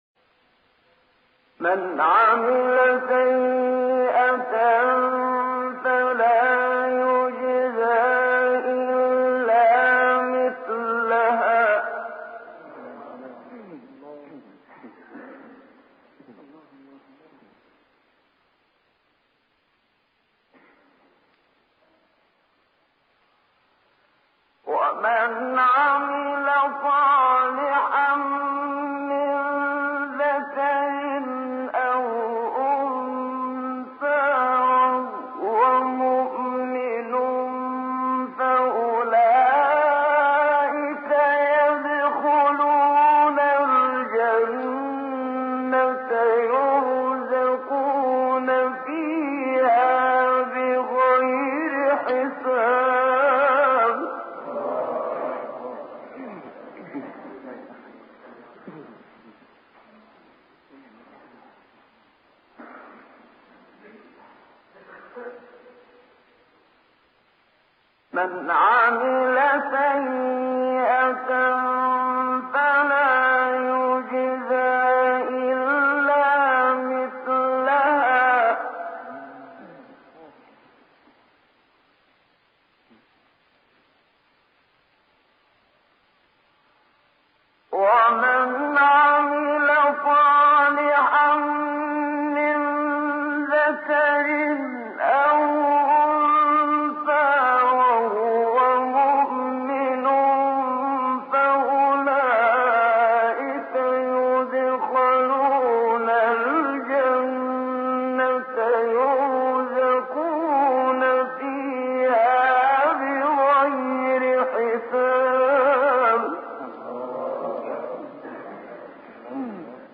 جالب اینکه این آیه نیز مضمون بلندی دارد و باز هم تلاوت همراه با تکرار است و هفت بار از این تکرارها در مقام بیات اجرا شده است.
این روند را منشاوی چندبار دیگر هم ادامه می‌دهد و با ماندن در مقام صبا بیش از پیش بر مضمون بلند آیه تأکید می‌کند.